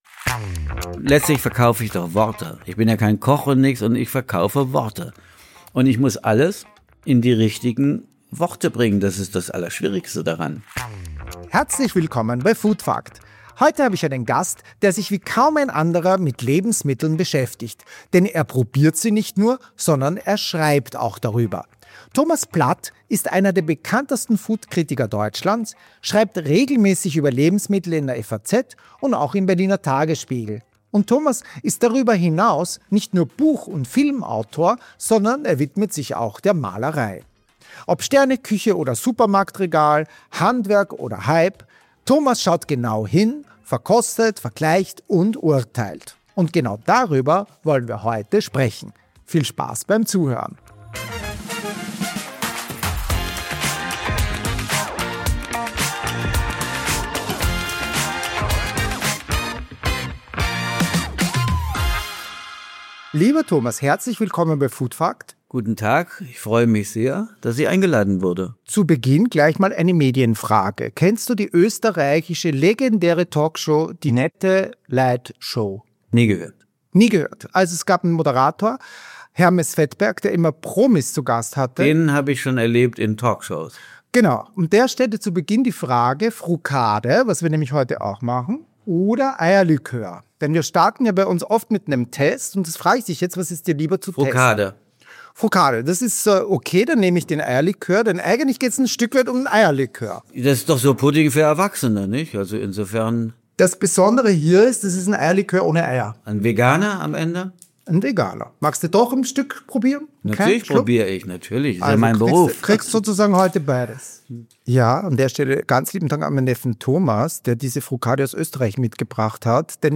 Im Interview spricht er über die Herausforderung, Geschmackserlebnisse sprachlich präzise und anschaulich zu formulieren, wobei er betont, wie schwierig es ist, Worte zu finden, die das komplexe Zusammenspiel von Aromen und Texturen wirklich einzufangen.